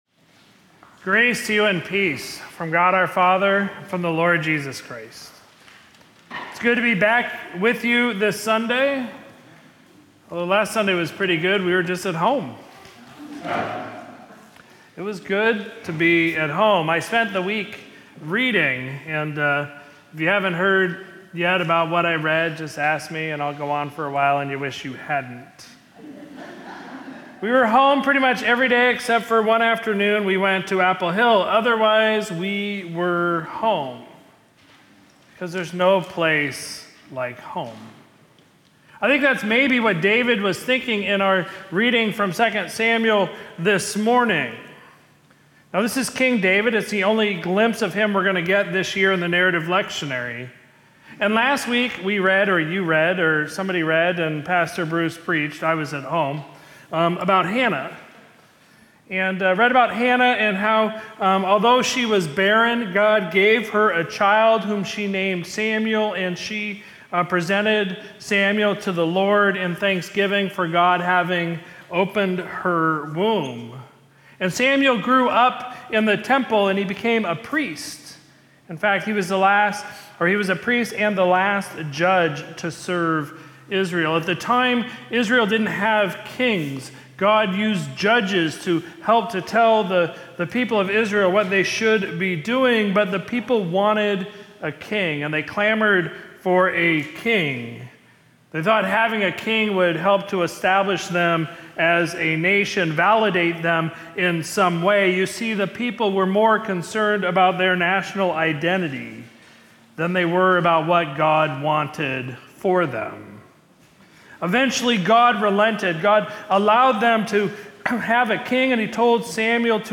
Sermon from Sunday, October 20, 2024
Ascension Lutheran Church – Citrus Heights, CA